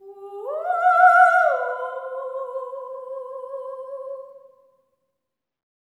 OPERATIC06-L.wav